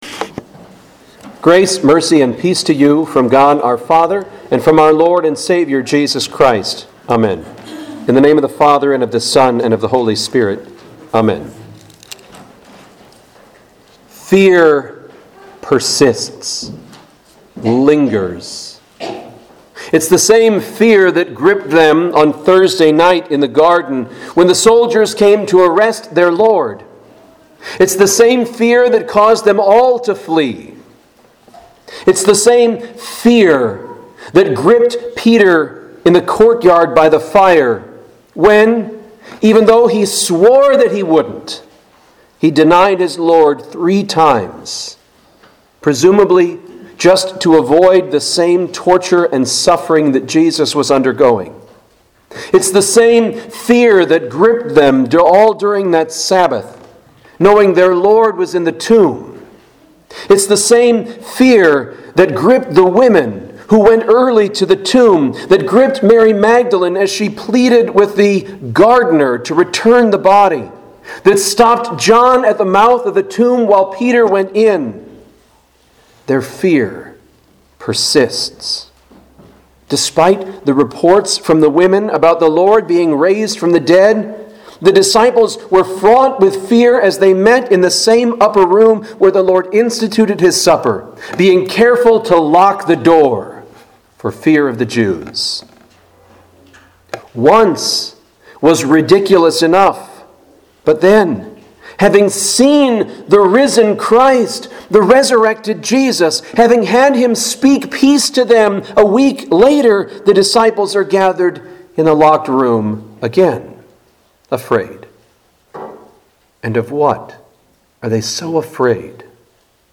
Home › Sermons › Quasimodo Geniti – Easter 2